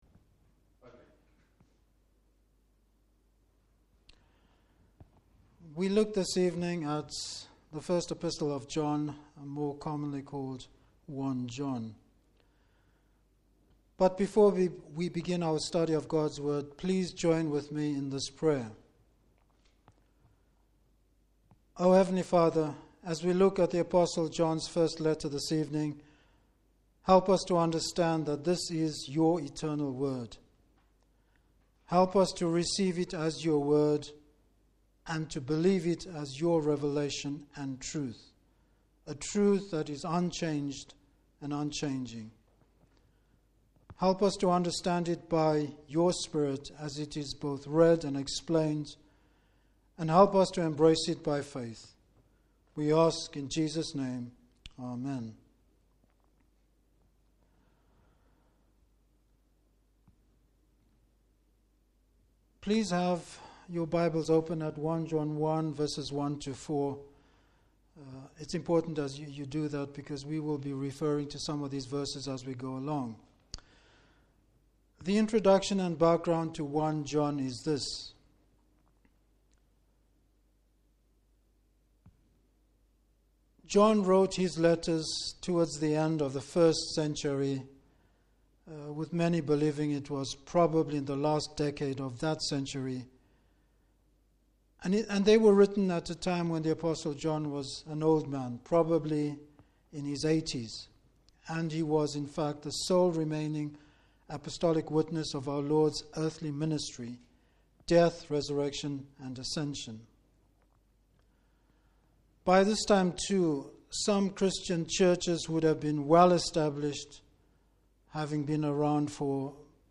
Service Type: Evening Service An introduction to the letter.